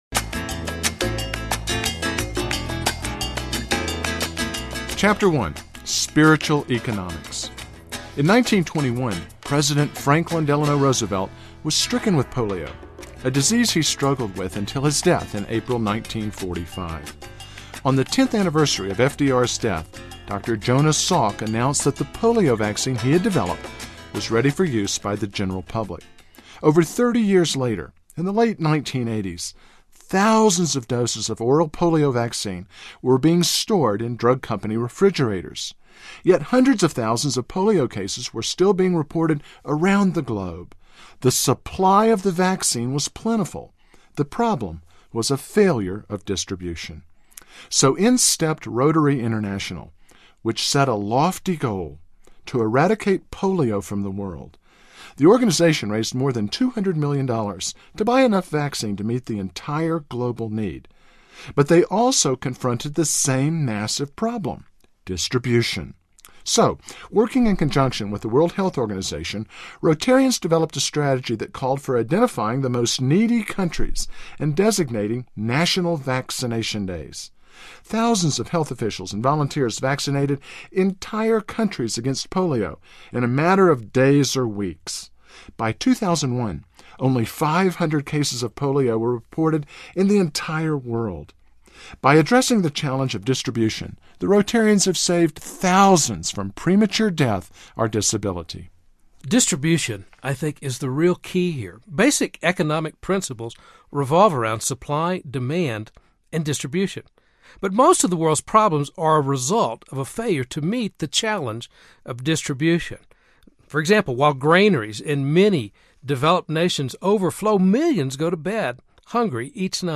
Going Public with Your Faith Audiobook
4.5 Hrs. – Abridged